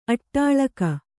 ♪ aṭṭāḷaka